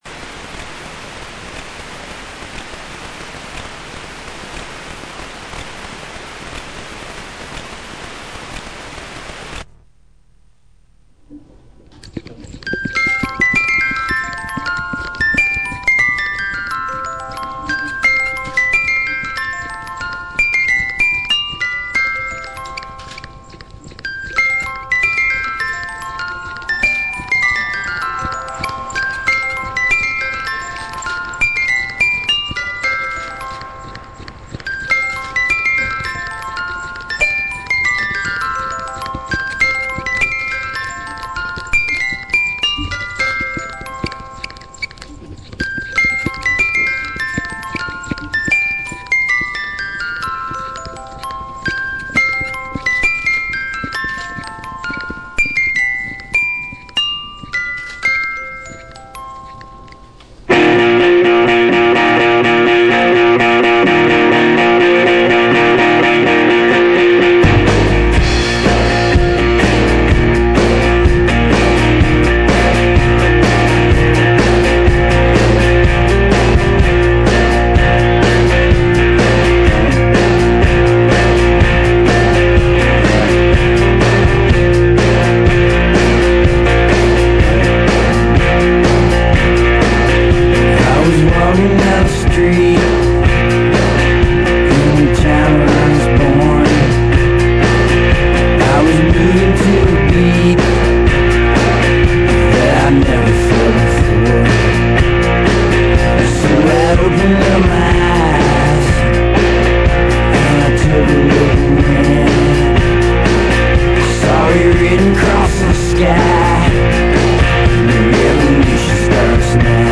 WRFU signing on
WRFU-signon.mp3